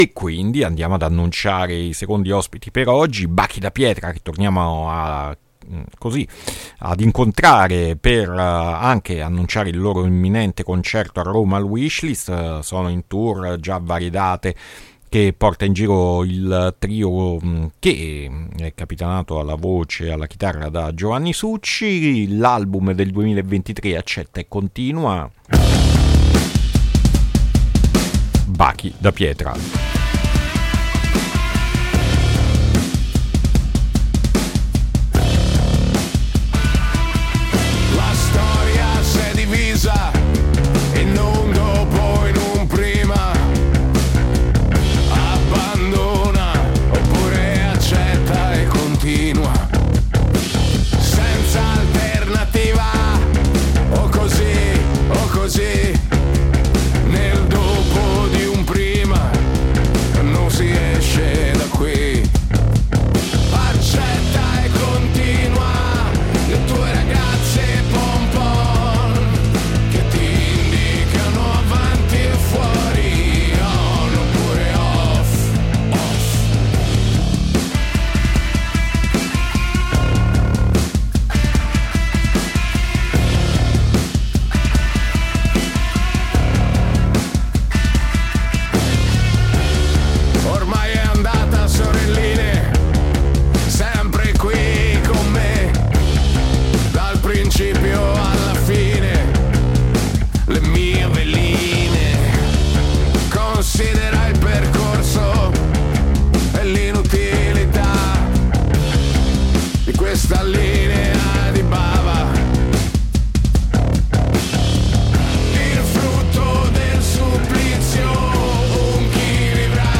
INTERVISTA BACHI DA PIETRA A MERCOLEDI' MORNING 8-5-2024